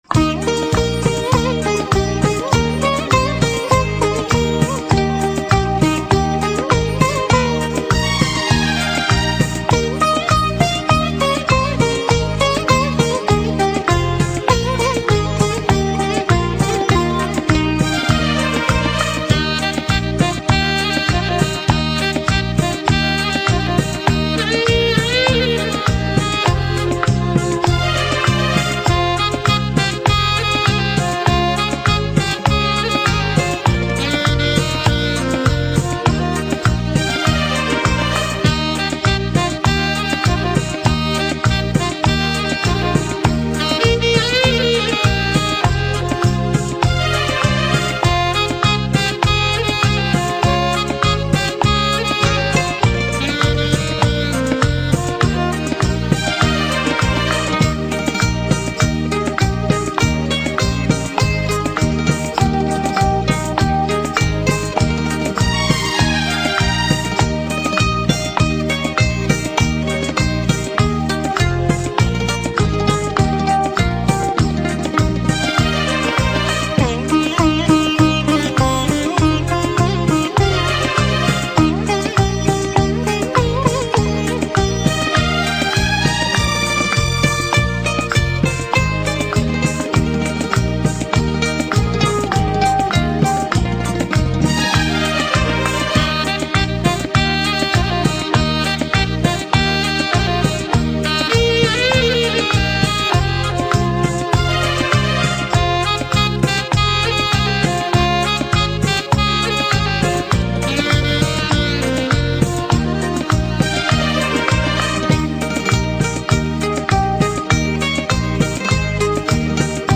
Sinhala Instrumental Mp3 Download